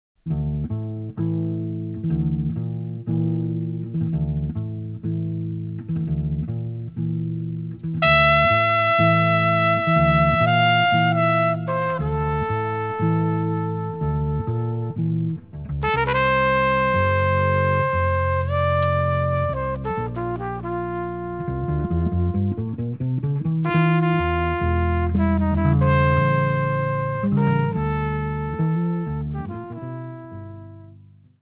double-bass, cello)hu